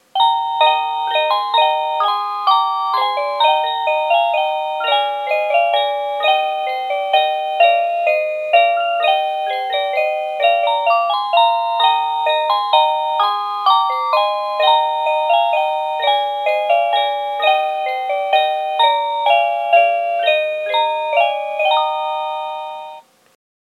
09-Cuckoo-Tune.mp3